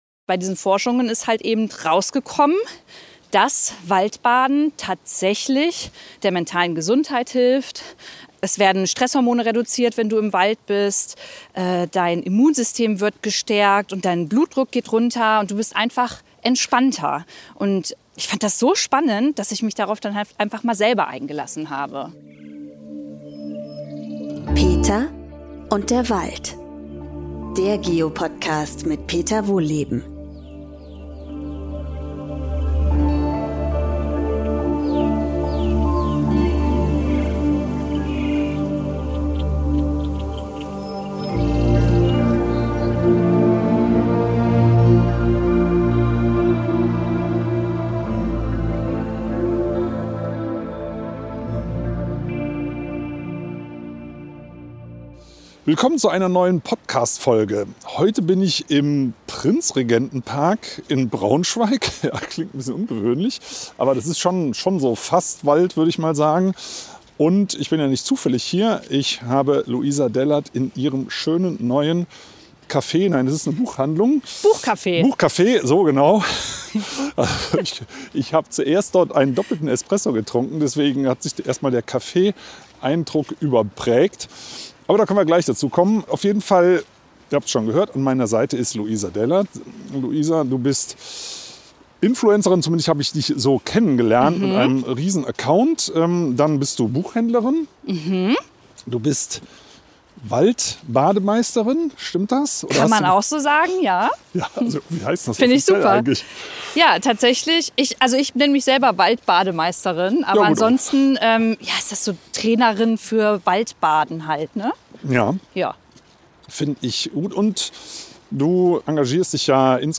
Bei einem entspannten Park-Spaziergang in Braunschweig reden die beiden über Persönliches und Spirituelles, aber vor allem über: Wald. Unter anderem besprechen sie, warum der Trend zur Jagd romantischer klingt, als er ist, dass Wald nachweislich heilt und warum man Menschen, inklusive sich selbt, öfter mal loben sollte.